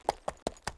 horse_walk.wav